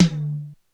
HiTom.wav